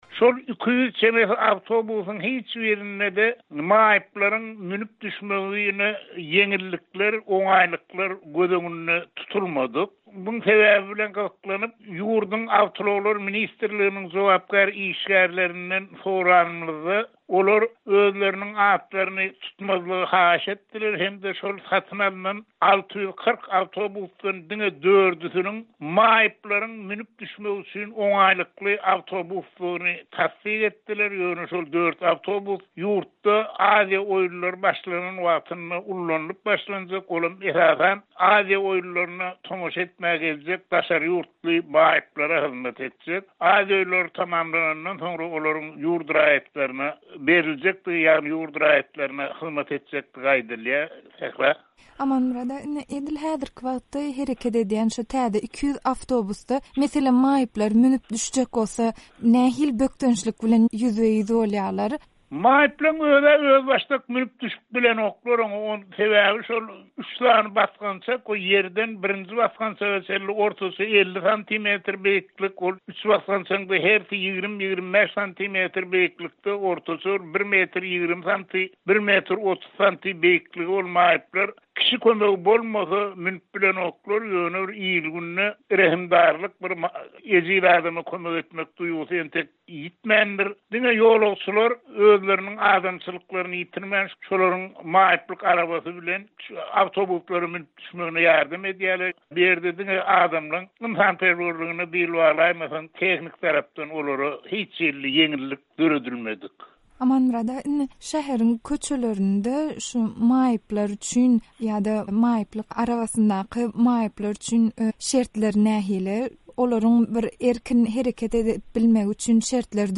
söhbetdeş boldy